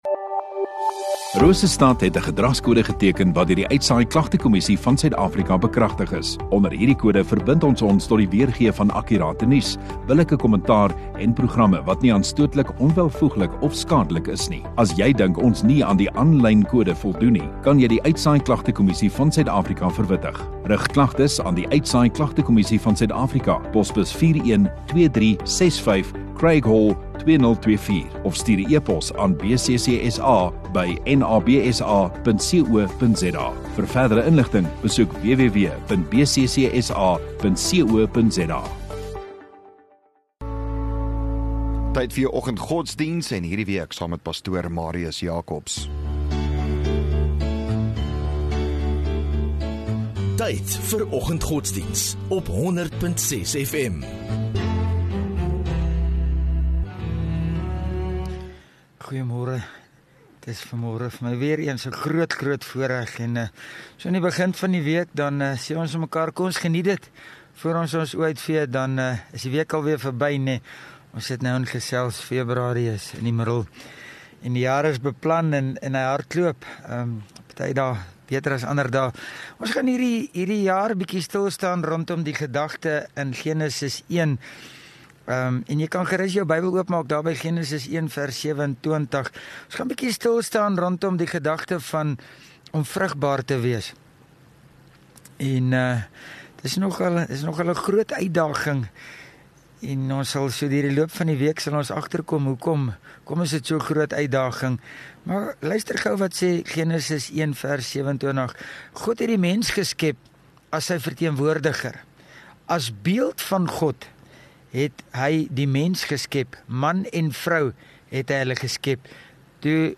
16 Feb Maandag Oggenddiens